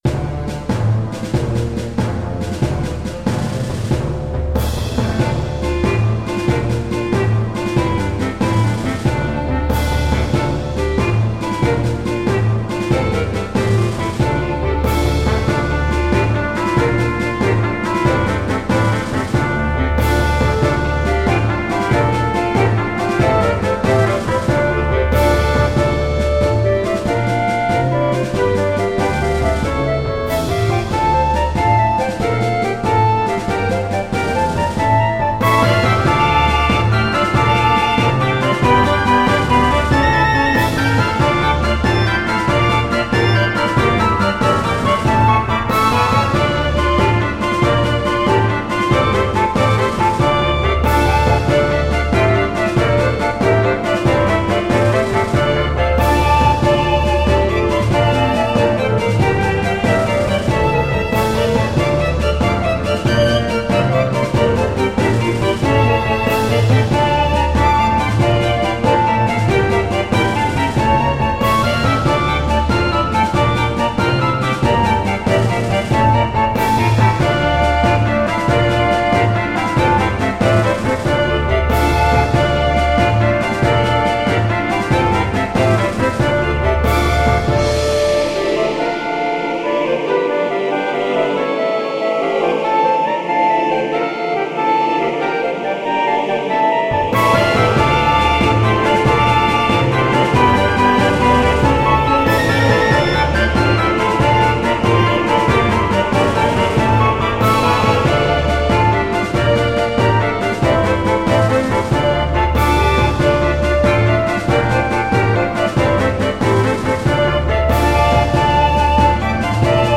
Palavras-chave: Foley